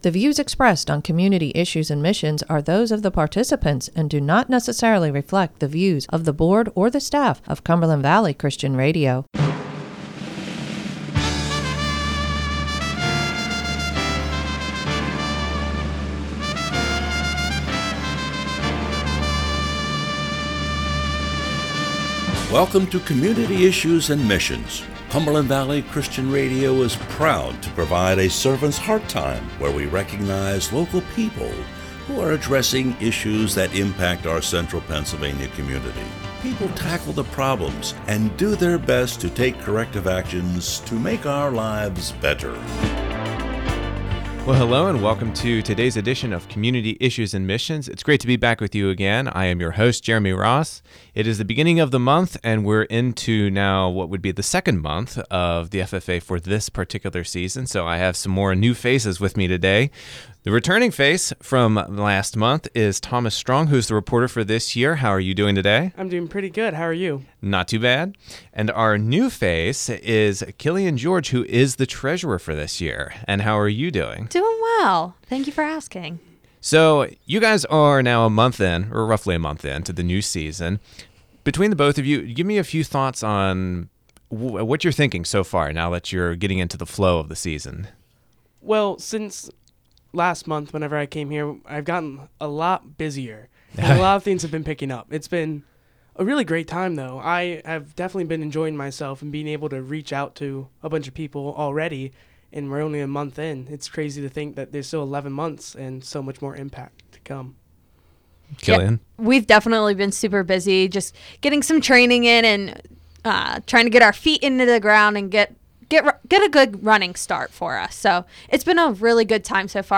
WPFG's Community Issues and Missions is a program where people in our community share their personal journey, their ministry, or their organization.